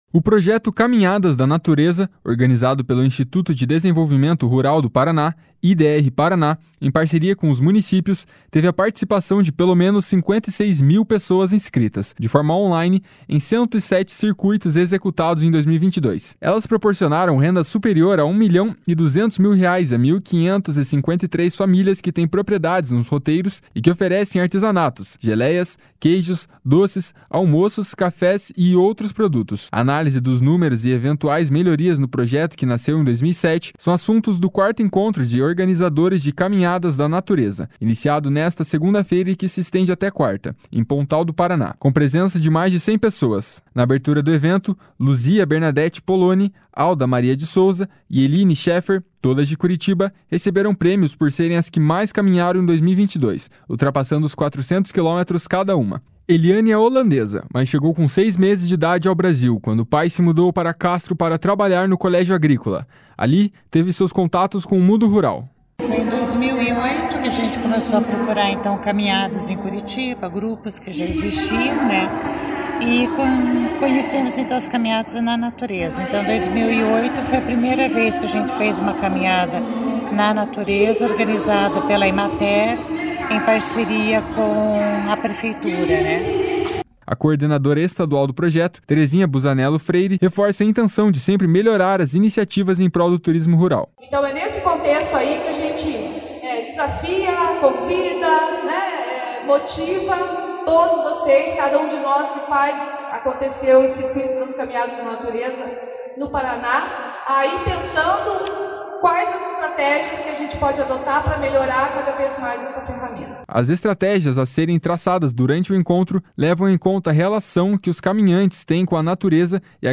TURISMO RURAL_0.mp3